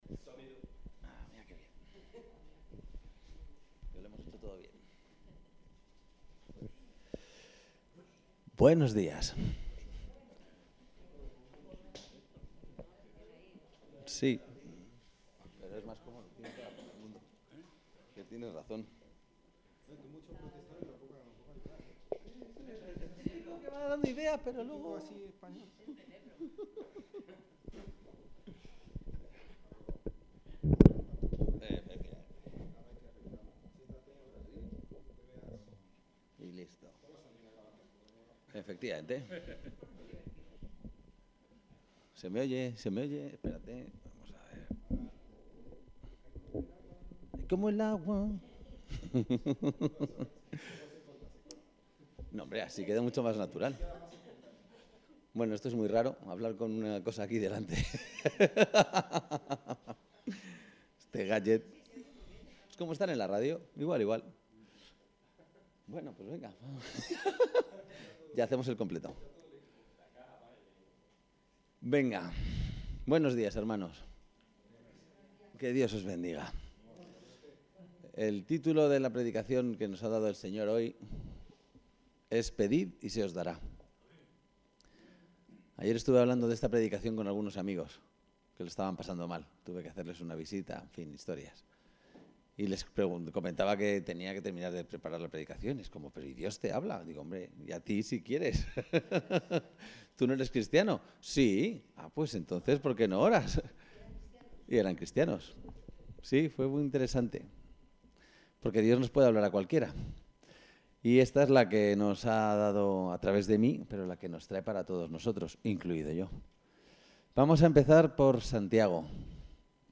El texto de la predicación se encuentra aquí:Pedid y se os dará